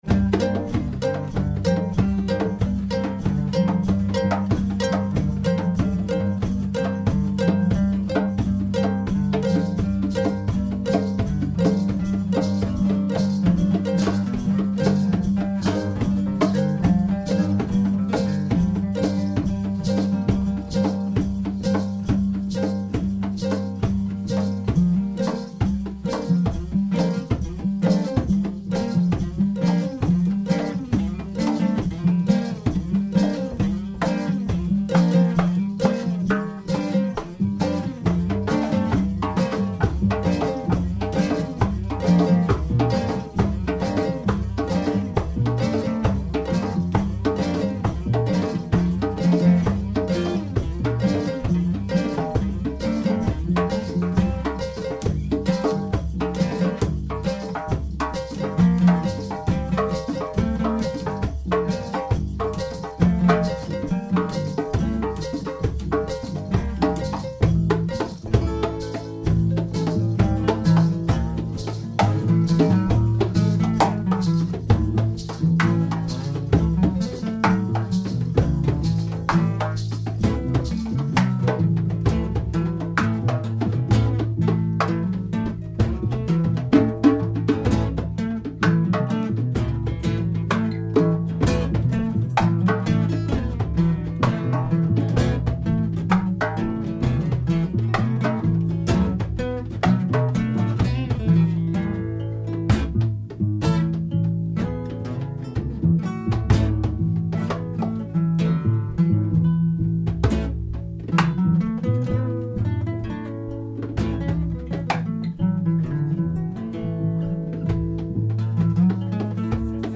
The 'Floating-Roster Ever-Expanding Live-Album Blog'